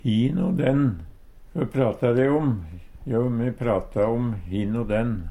hin o den - Numedalsmål (en-US)